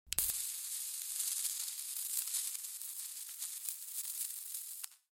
空间/未来SFX " 电力1
描述：电噪声。
Tag: 嘶嘶声 电气 电力